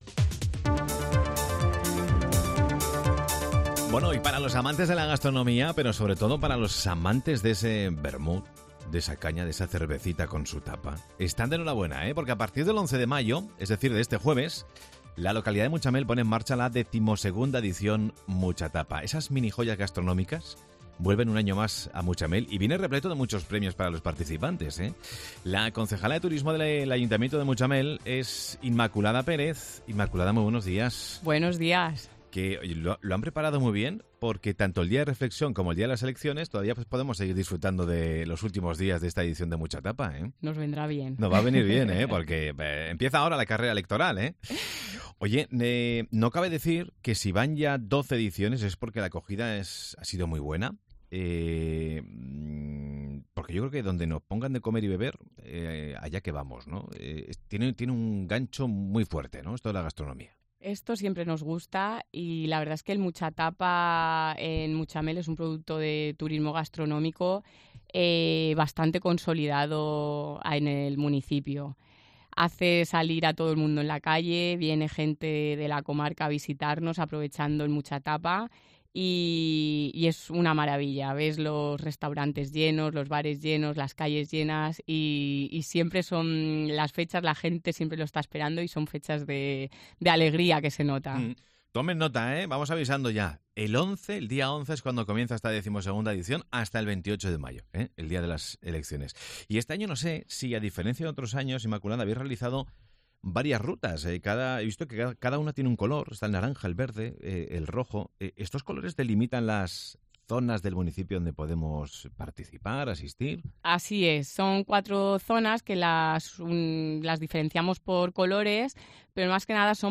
El evento que se celebrará del 11 al 28 de mayo, contará con la participación de más de 20 establecimientos del municipio. Escucha la entrevista a Inmaculada Pérez, edil de Turismo